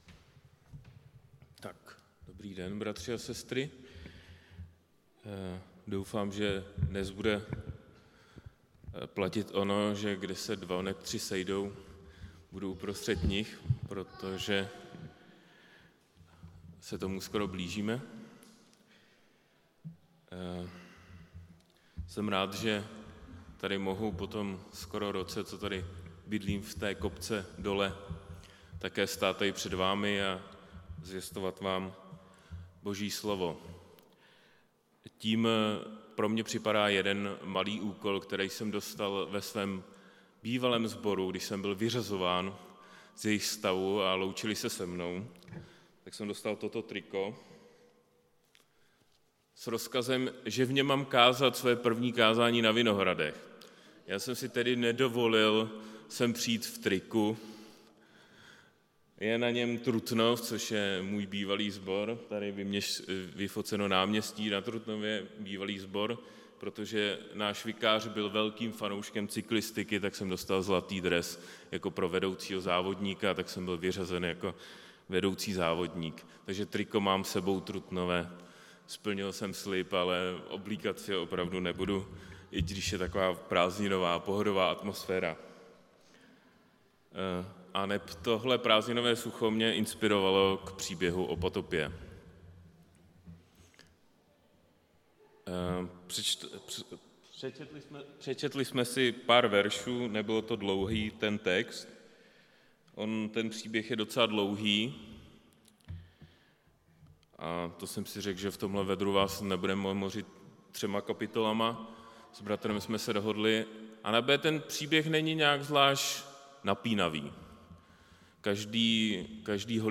Kázání
Místo: Římská 43, Praha 2